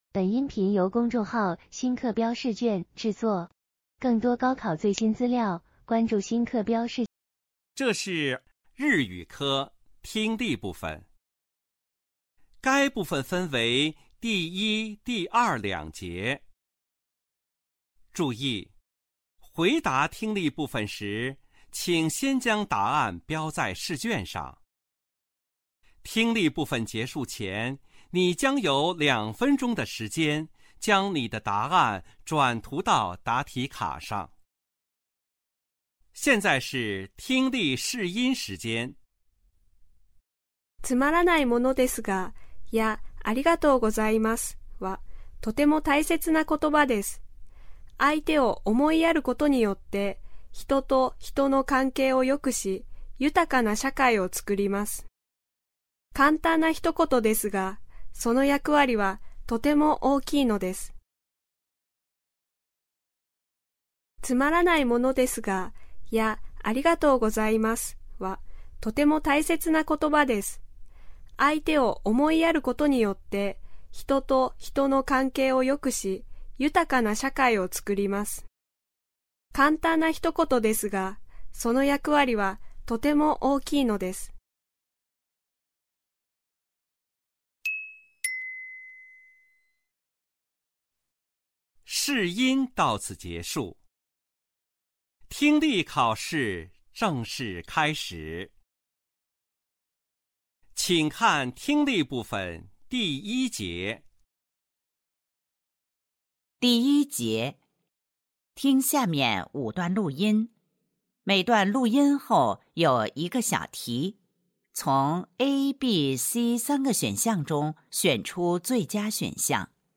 2024年12月江苏金太阳百校联考日语试题及答案 江苏金太阳百校25上12月日语听力.mp3